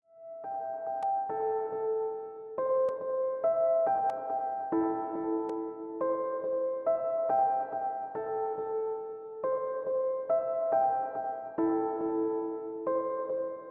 Cloakaine_Piano.wav